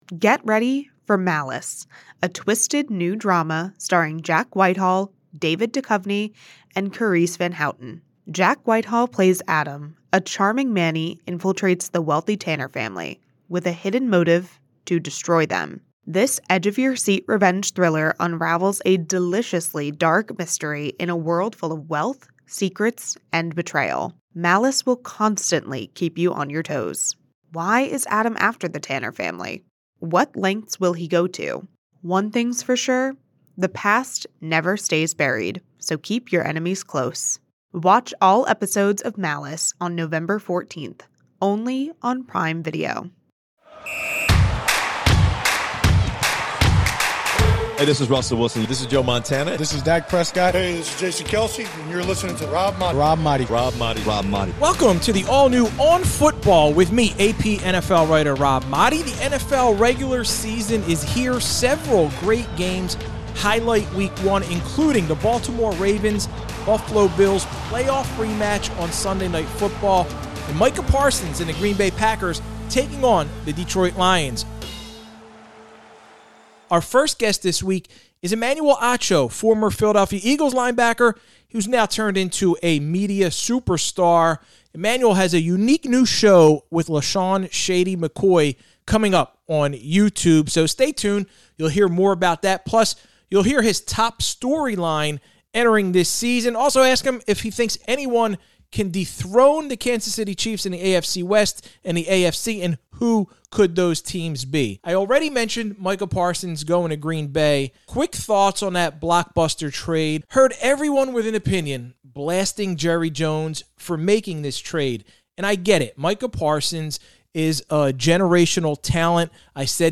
Also, a conversation with former NFL player and sports broadcaster, Emmanuel Acho.